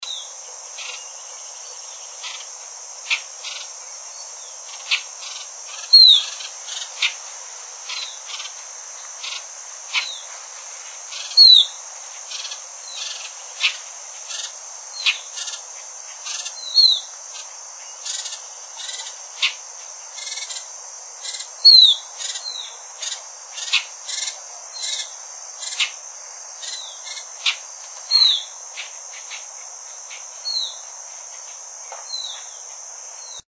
Tordo Músico (Agelaioides badius)
Nombre en inglés: Greyish Baywing
Localidad o área protegida: Reserva Ecológica Costanera Sur (RECS)
Condición: Silvestre
Certeza: Vocalización Grabada